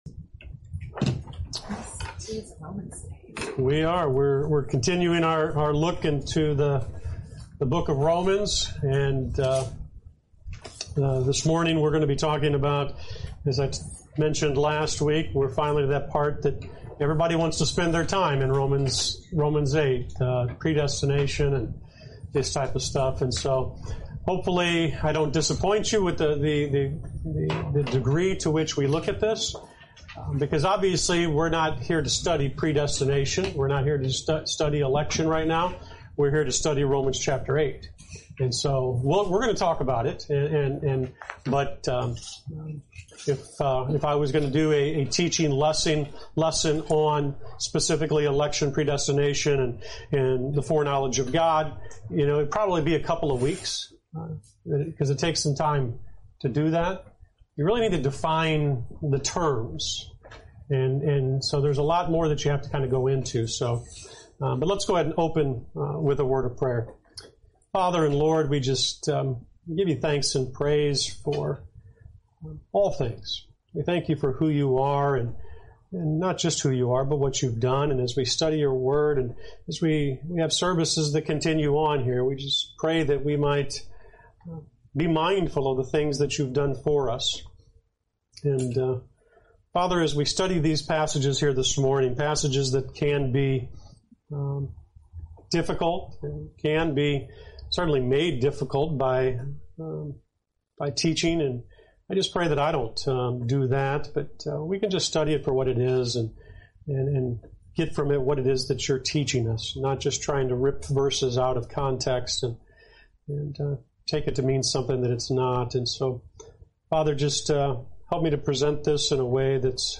When you're done, explore more sermons from this series.